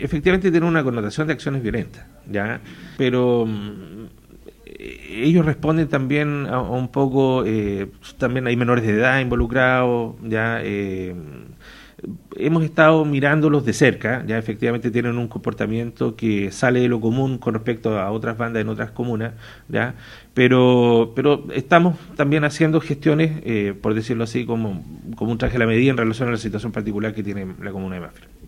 El seremi de Seguridad Pública, Cristian Winter, reconoció que estas bandas salen de lo común en la región, siendo vigiladas de cerca.